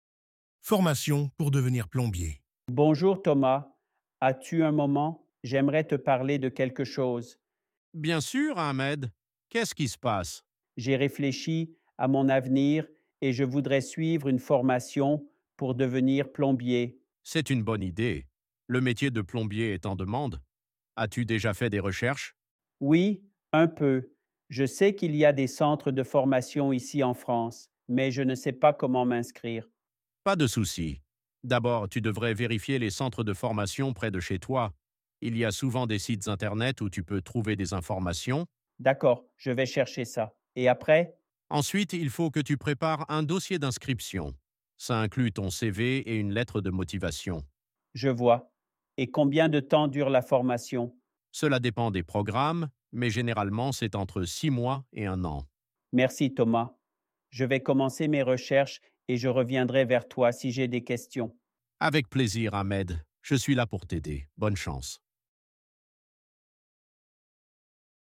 Dialogue – Formation pour devenir plombier (Niveau B1)